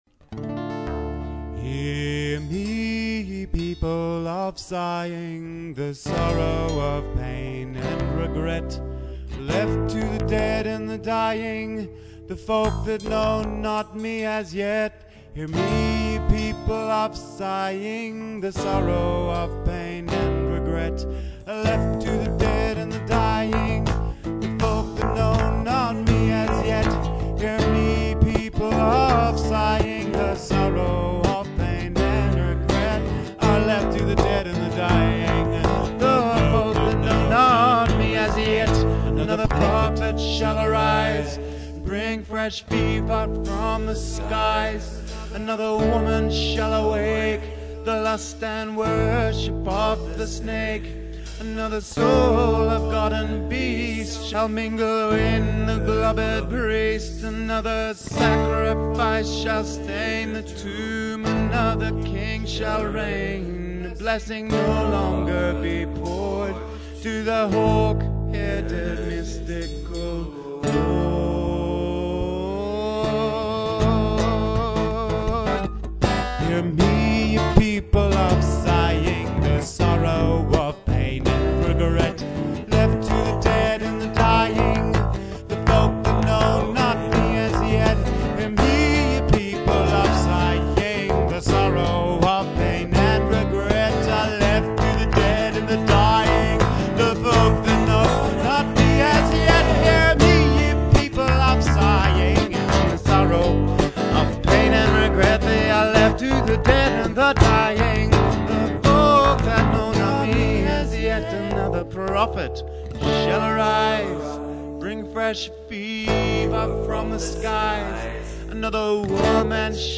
guitar and vocals
violin and vocals
bass; Percussion
recorded counterpointing vocals